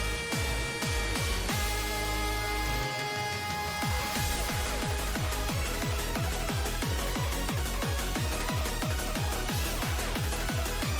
Identify eurobeat style song